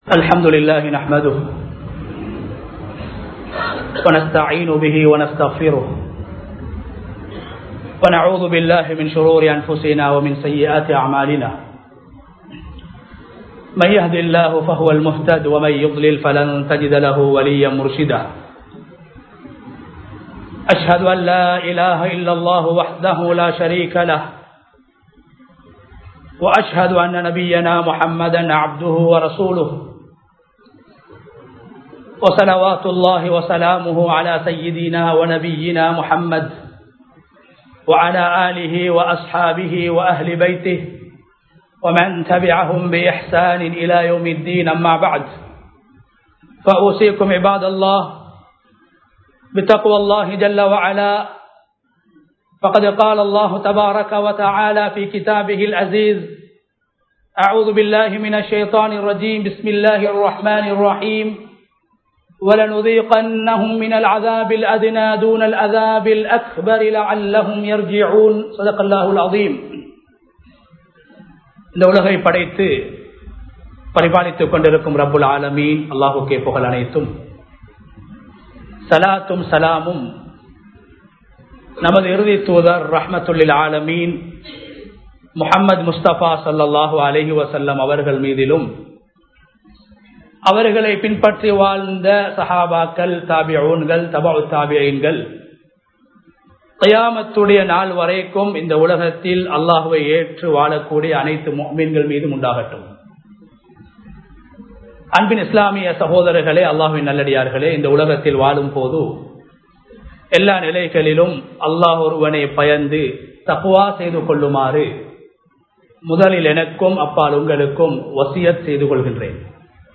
முஃமின் தவறை மீண்டும் செய்யமாட்டார் | Audio Bayans | All Ceylon Muslim Youth Community | Addalaichenai